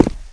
FOOTSTEP
FOOTREG3.WAV